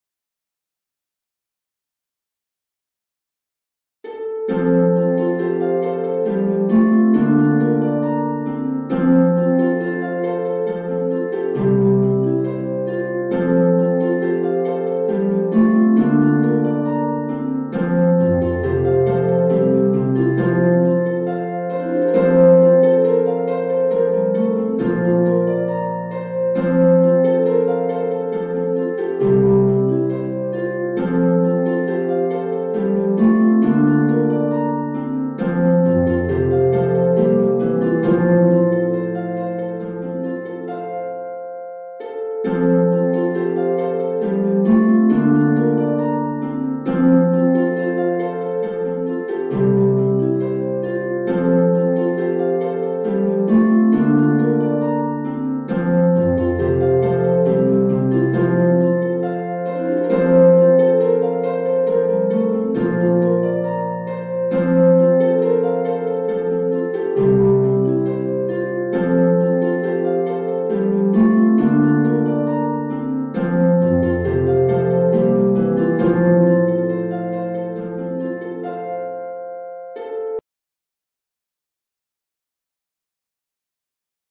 Harp duet.